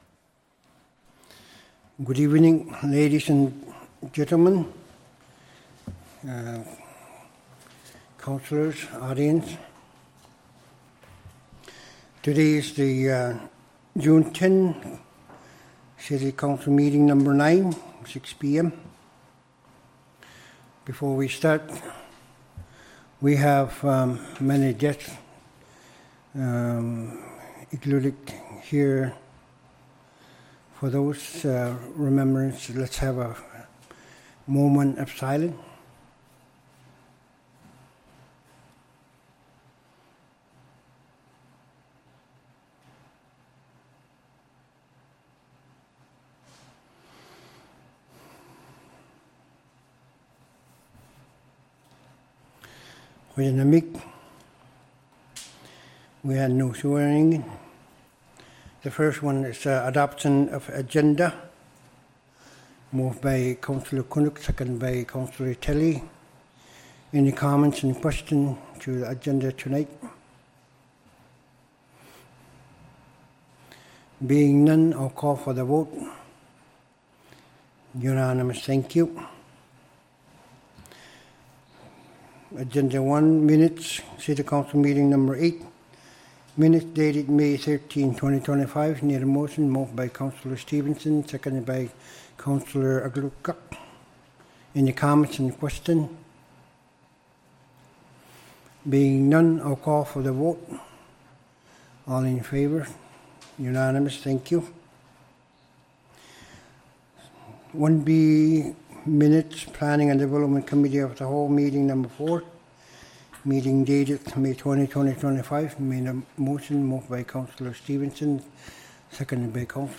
City Council Meeting # 09 | City of Iqaluit